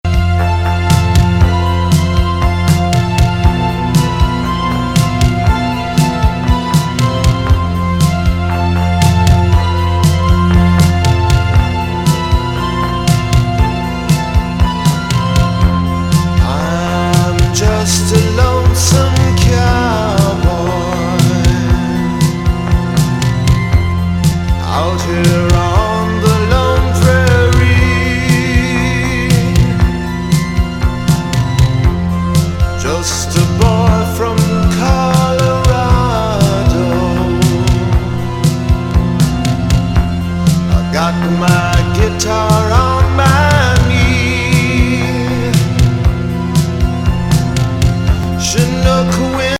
プログラミングによるミニマルなリズムとスペーシーNW
全編通してトリッピー!